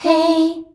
HEY     E.wav